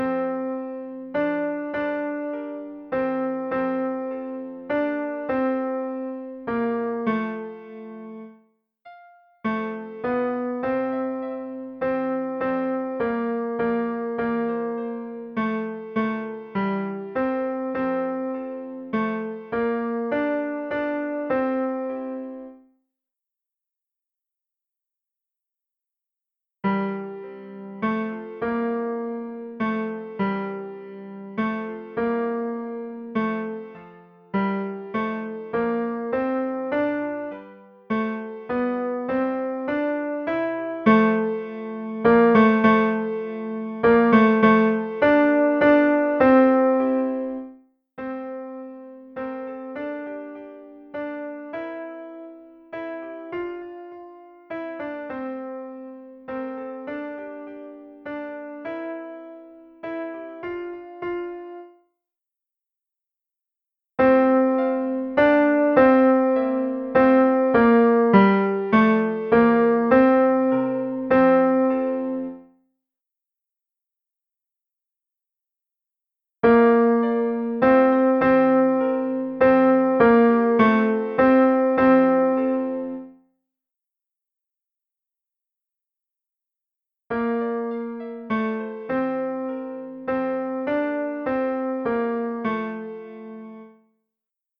Ténors
viens_dans_ce_sejour_tenors.mp3